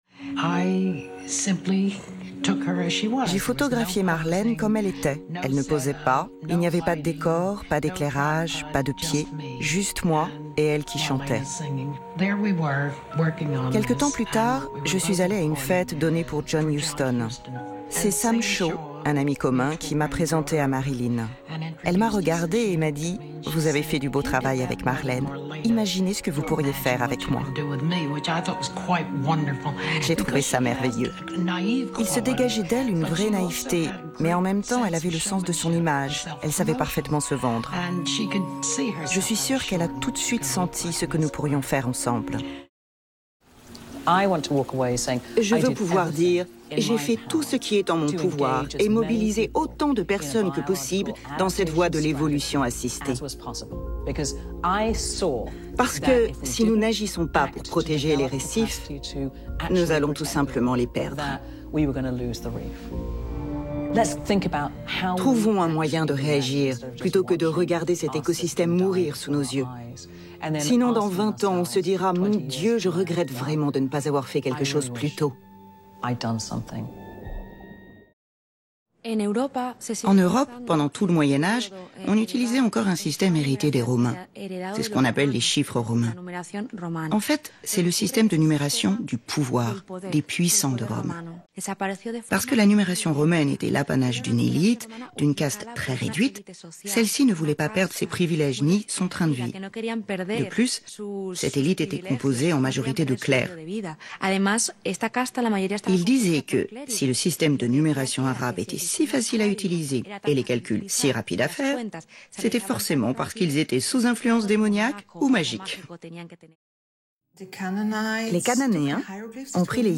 Bande démo voix (narration, voice over...)
30 - 55 ans - Mezzo-soprano